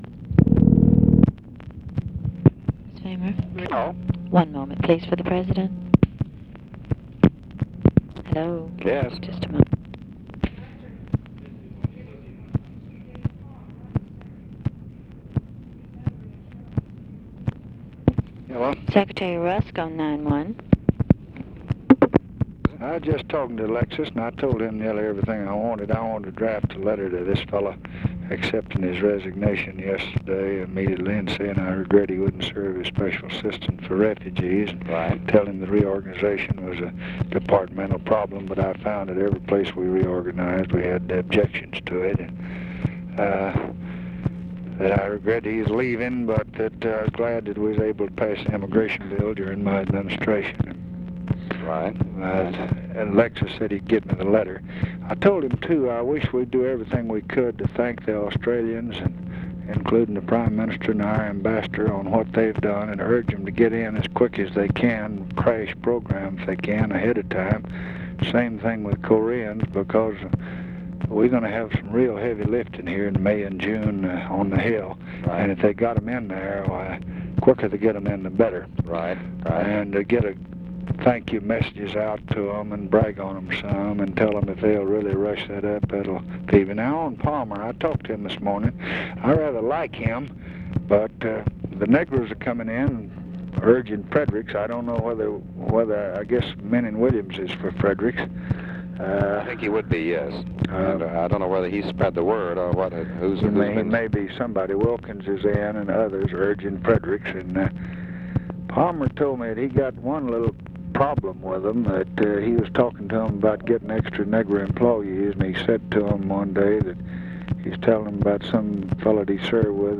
Conversation with DEAN RUSK, March 7, 1966
Secret White House Tapes